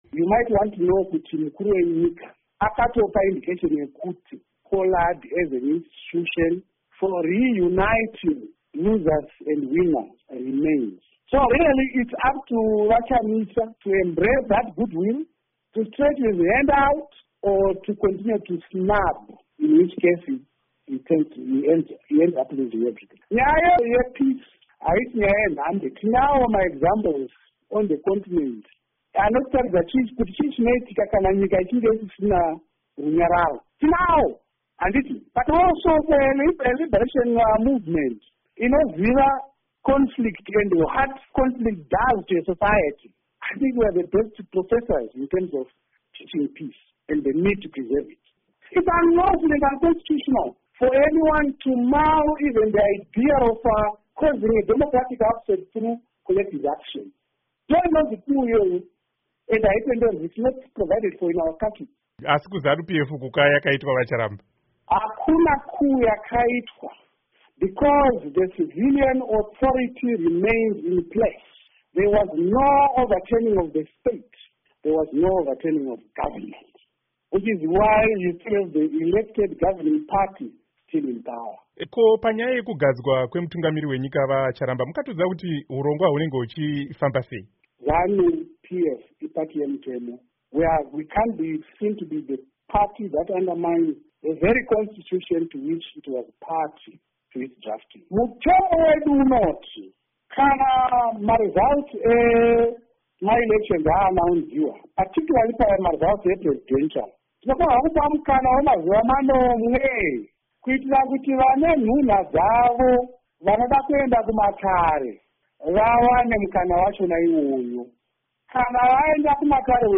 Hurukuro naVaGeorge Charamba